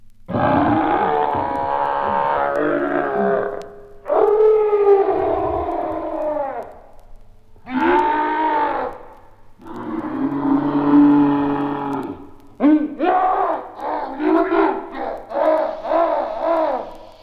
• the mad gorilla - scary.wav
Recorded from Sound Effects - Death and Horror rare BBC records and tapes vinyl, vol. 13, 1977.